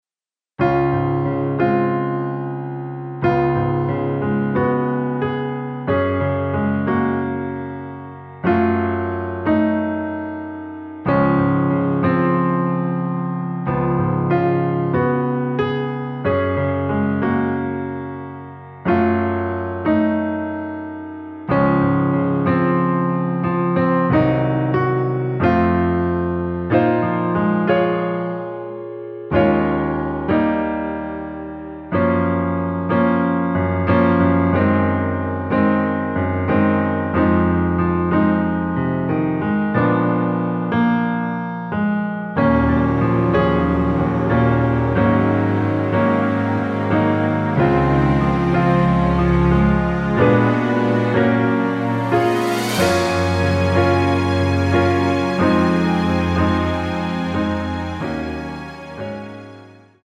Db
◈ 곡명 옆 (-1)은 반음 내림, (+1)은 반음 올림 입니다.
앞부분30초, 뒷부분30초씩 편집해서 올려 드리고 있습니다.